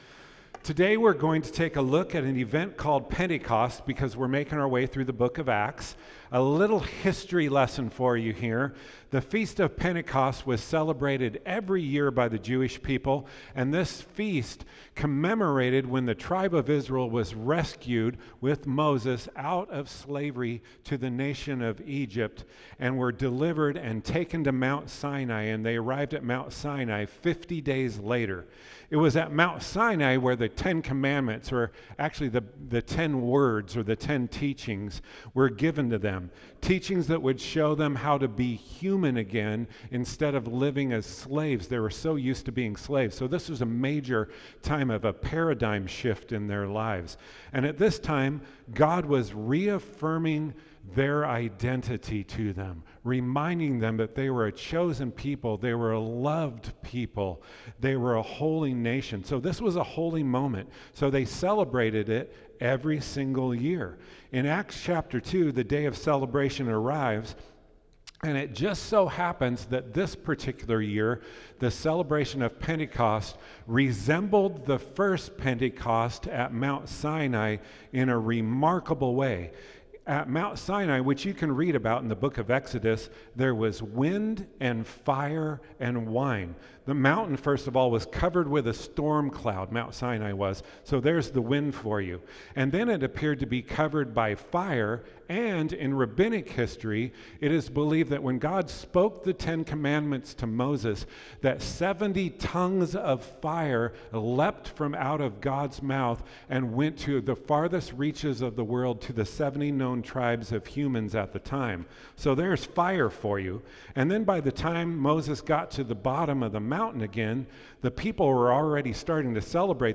Sermons | Faith Avenue Church